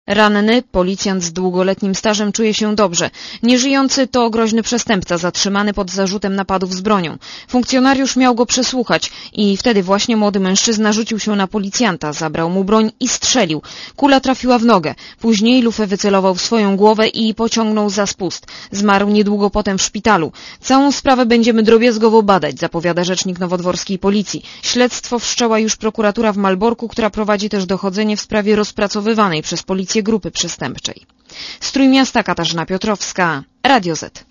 Źródło zdjęć: © Archiwum 16.09.2003 07:17 ZAPISZ UDOSTĘPNIJ SKOMENTUJ © (RadioZet) Posłuchaj relacji (136 KB)